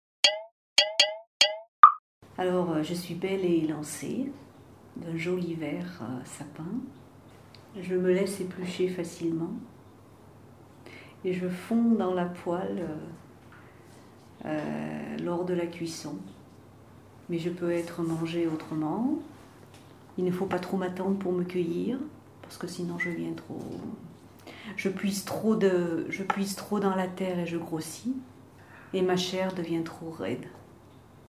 …des devinettes sonores